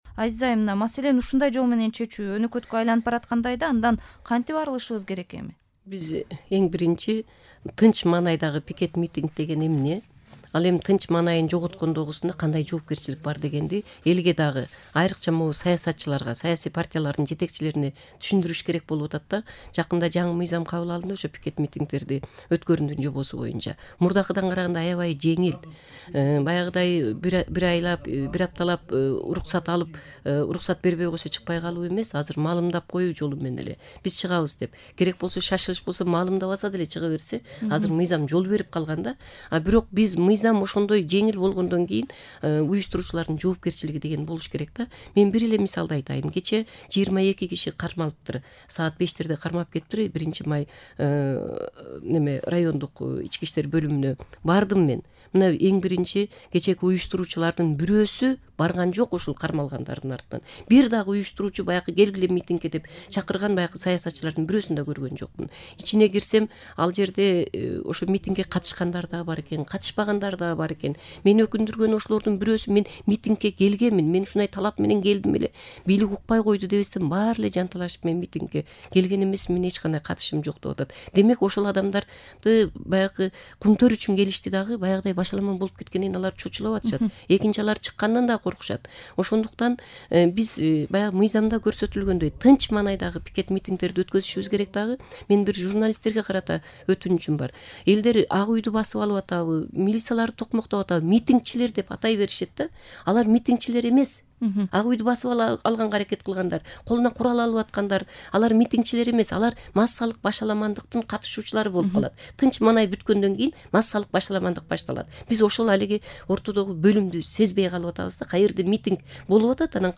Embed бөлүшүү Талкуунун экинчи бөлүгү автор Азаттык Үналгысы | Кыргызстан: видео, фото, кабарлар Embed бөлүшүү The code has been copied to your clipboard.